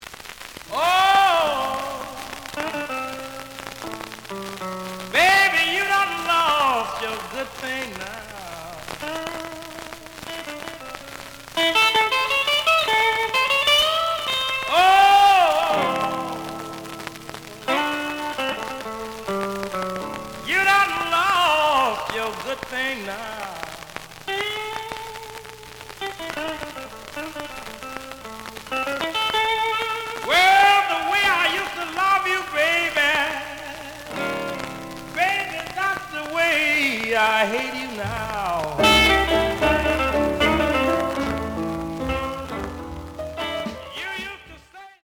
The audio sample is recorded from the actual item.
●Genre: Blues
Some noise on both sides.)